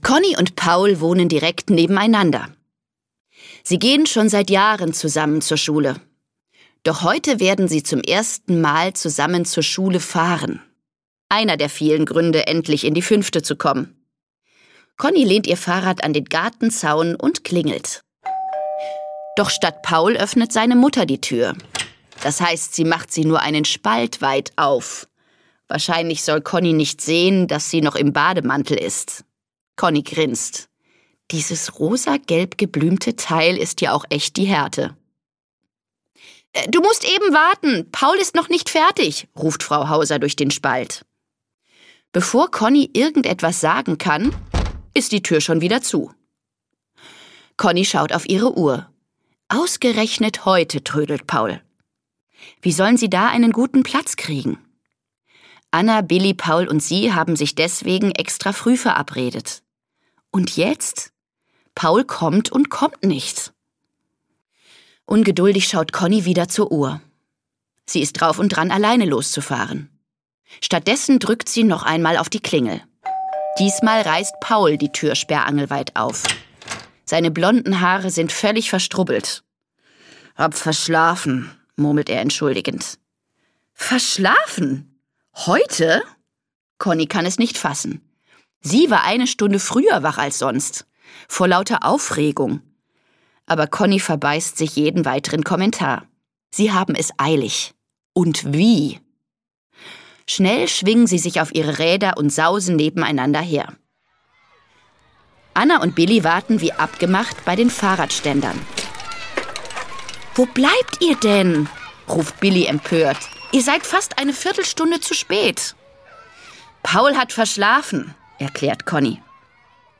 Conni & Co 1: Conni & Co - Julia Boehme - Hörbuch